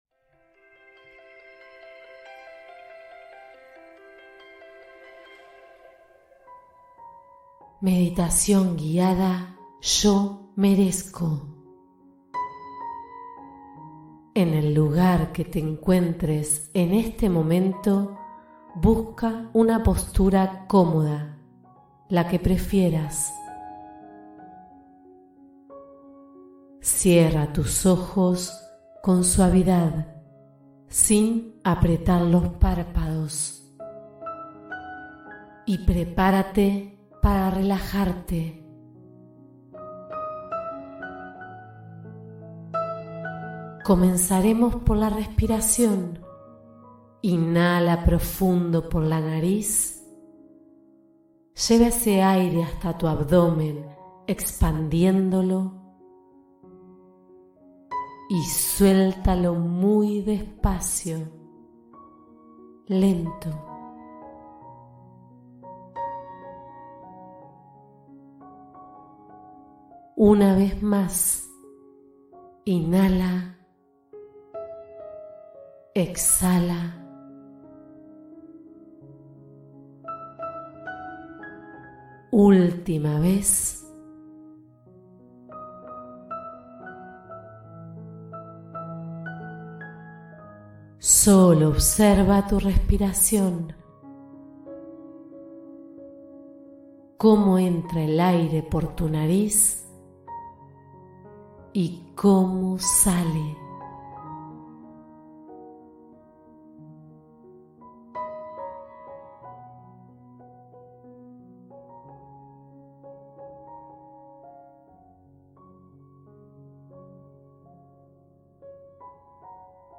Reconocer tu Merecimiento: Meditación para Activar Poder Interno